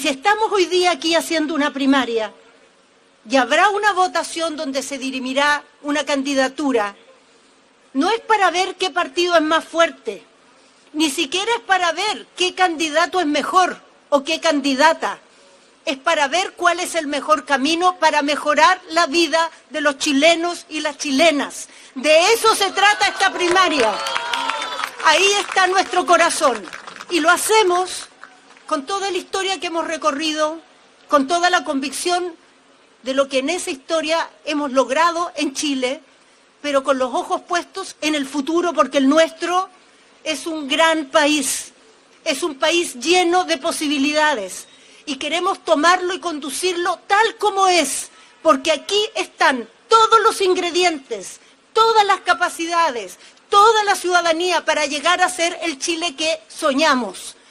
Carolina Tohá, ex titular de Interior y candidata del Socialismo Democrático (PPD, PS, PL, PR), manifestó que “para llegar a esta primaria ha habido generosidad, responsabilidad y poner a Chile primero”.